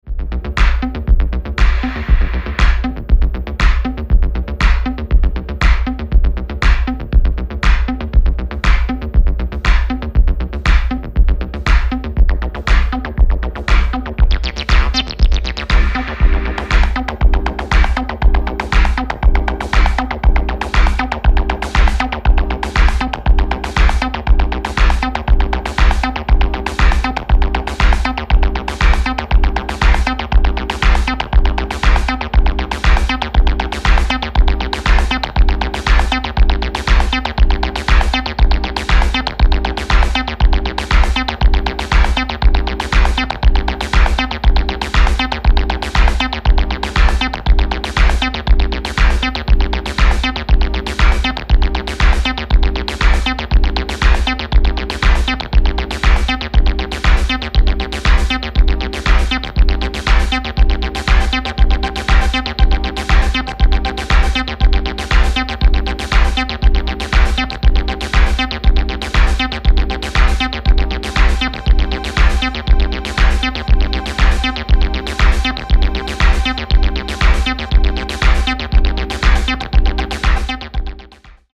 グッド・ディープ・ハウス！！
ジャンル(スタイル) DEEP HOUSE